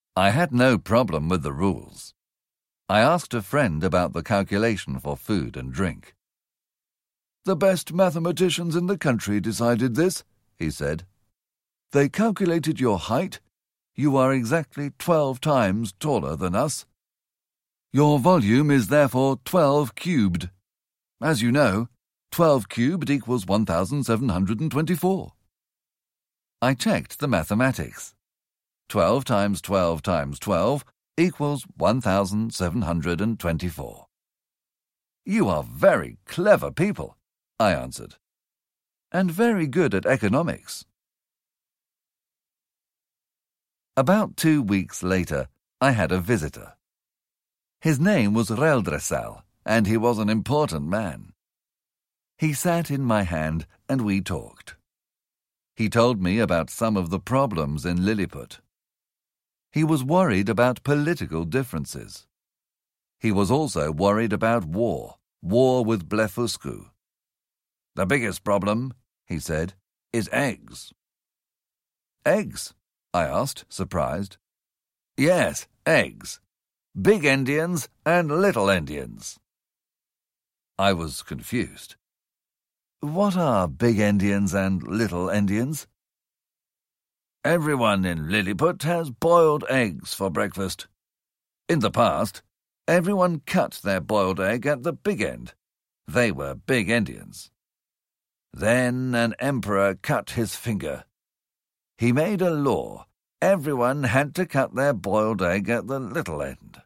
Gulliver's Travels (EN) audiokniha
Ukázka z knihy